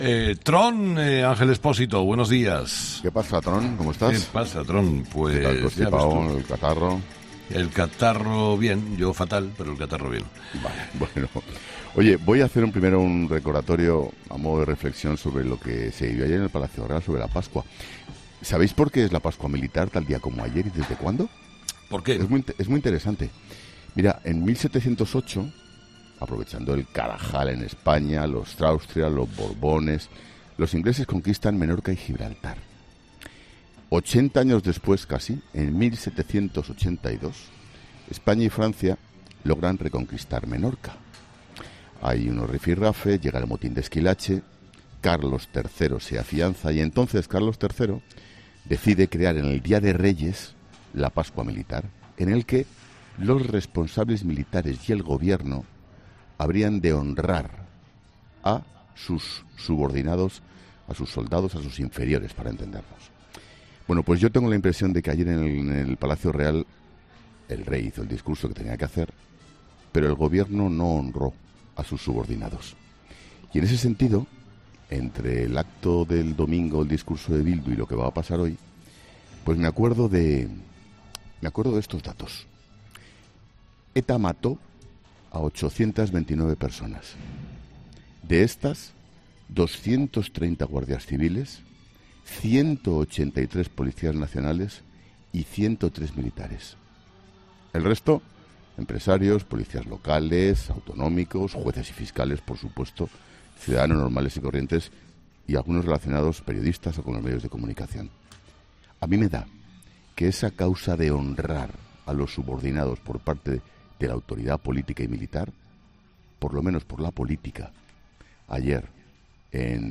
Ángel Expósito hace su paseíllo en 'Herrera en COPE'